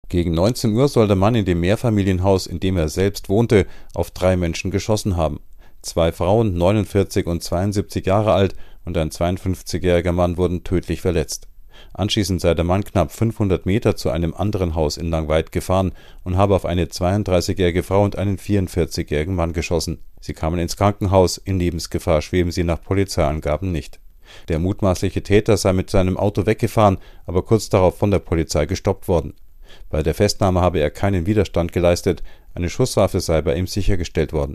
Nachrichten 64-Jähriger erschießt drei Menschen